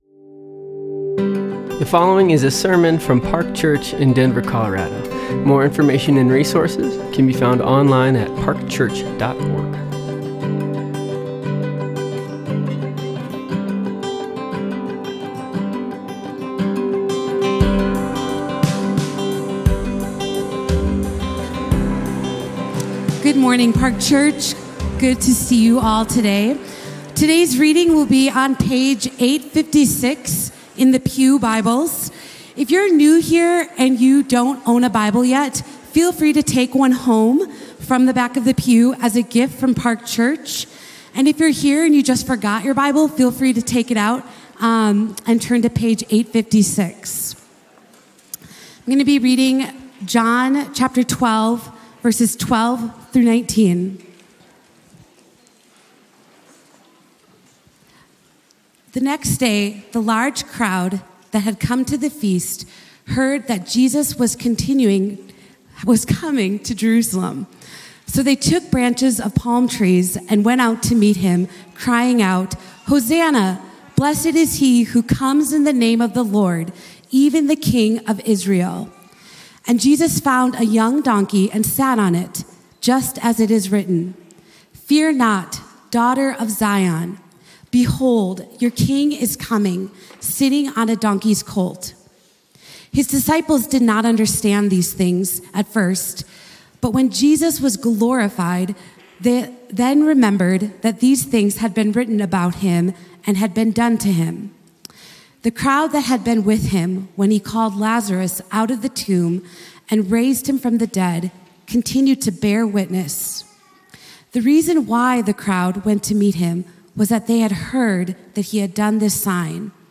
Palm Sunday: John 20:24–31 | Park Church